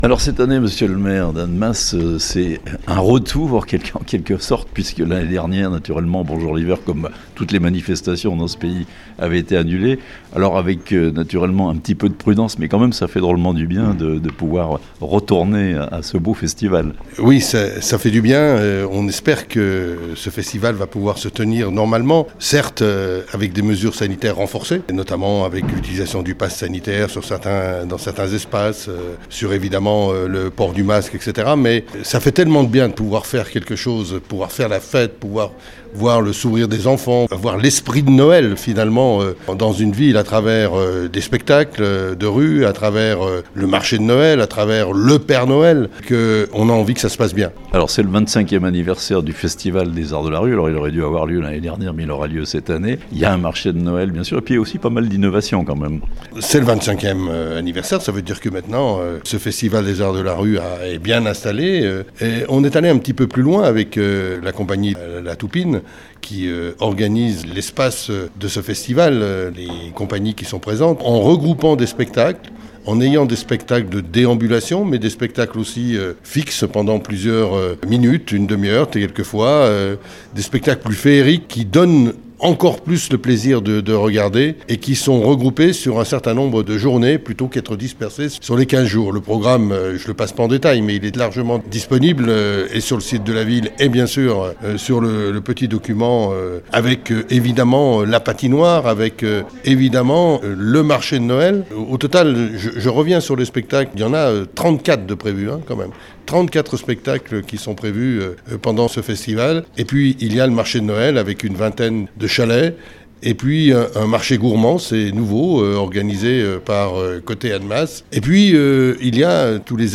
A Annemasse, une fin d’année festive avec "Bonjour l’Hiver" (interview)
Présentation de "Bonjour l'Hiver" édition 2021 par Christian Dupessey, Maire d'Annemasse